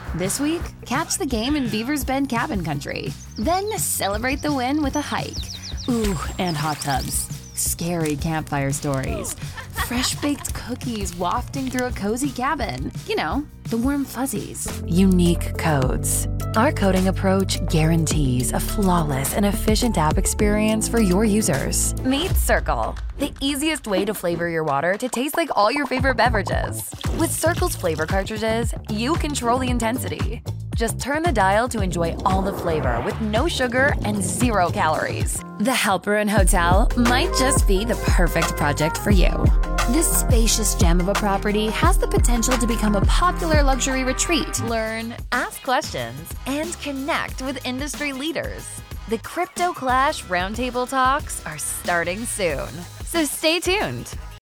Young yet gritty voice, specializing in natural, laid back delivery!
Q4 2024 Commercial Demo